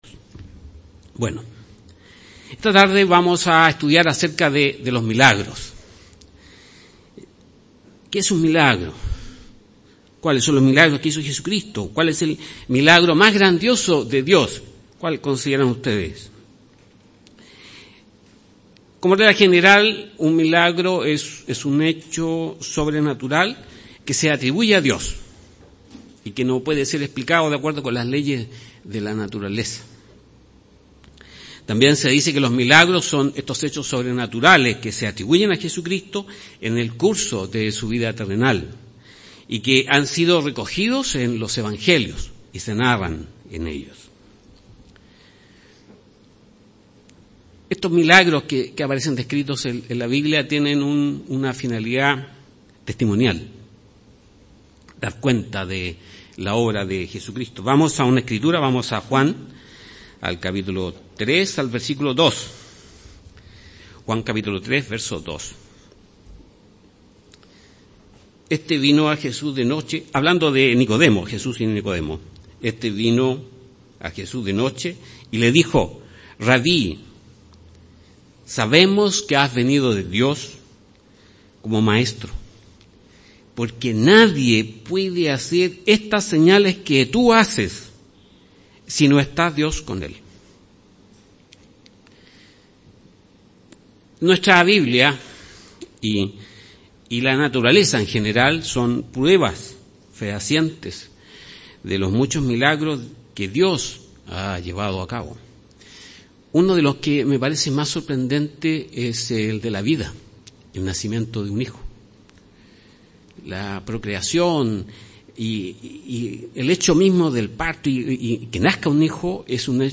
Given in Santiago